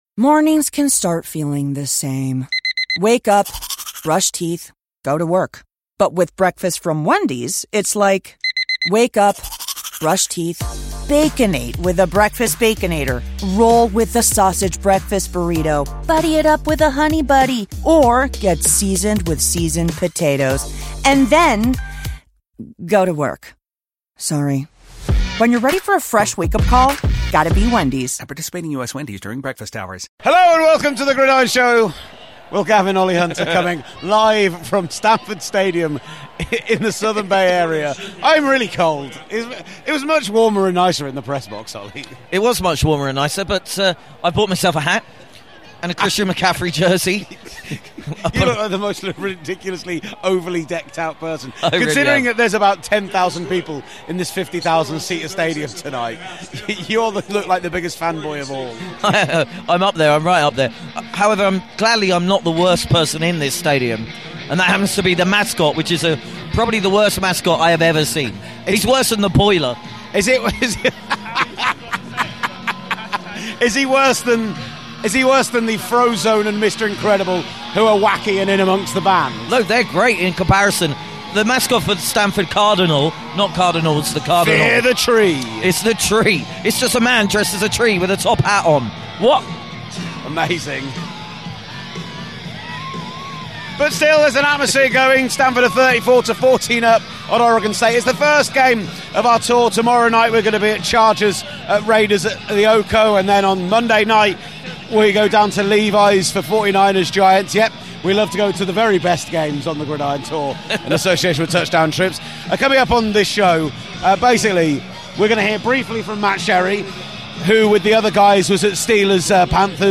TGS 243 - Live from Stanford Stadium